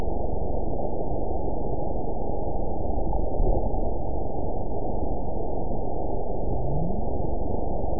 event 914429 date 05/08/22 time 23:45:07 GMT (3 years ago) score 8.98 location TSS-AB05 detected by nrw target species NRW annotations +NRW Spectrogram: Frequency (kHz) vs. Time (s) audio not available .wav